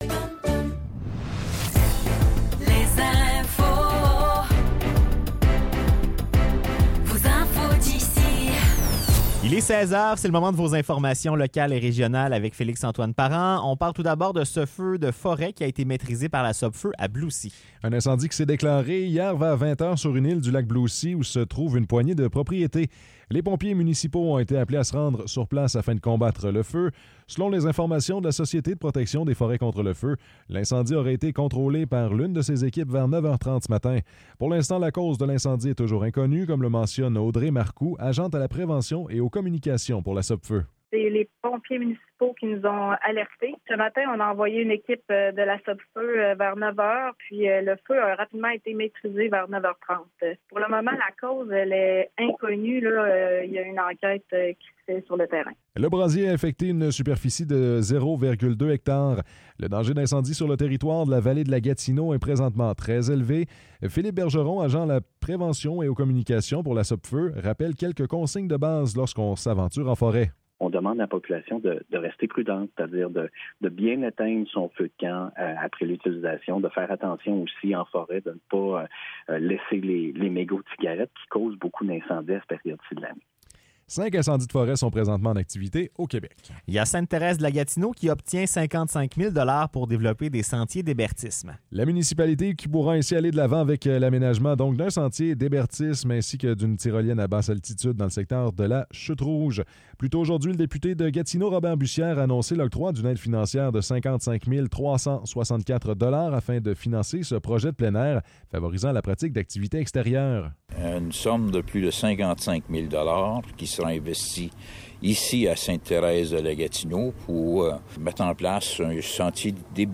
Nouvelles locales - 30 juillet 2024 - 16 h